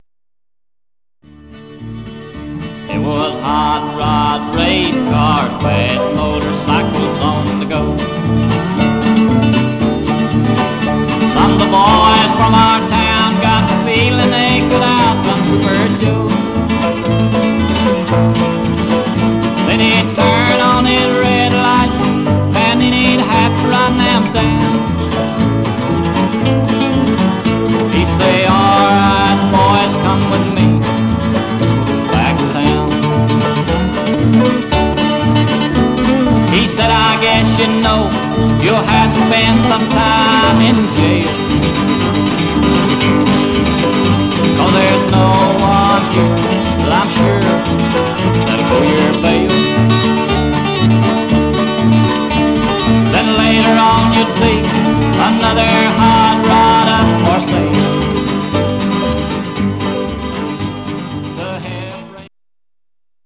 Some surface noise/wear
Mono
Country